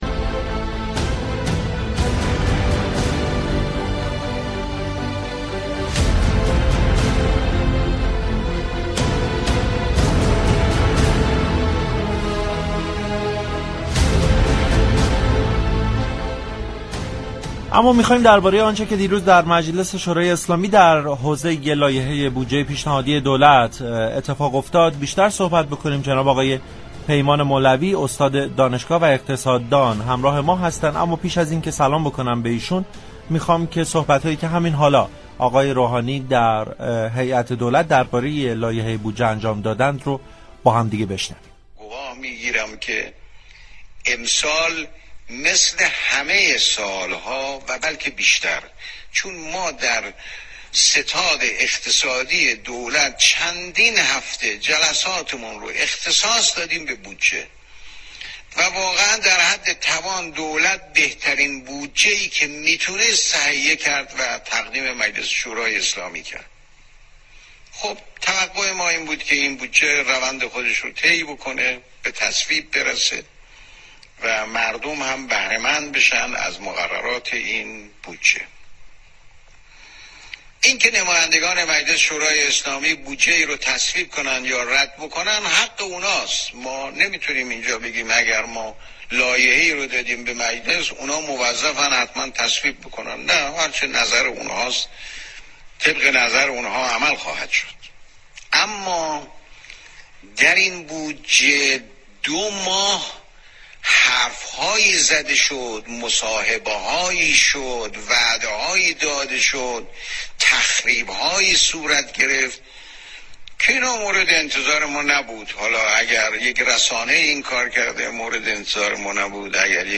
در گفتگو با برنامه بازار تهران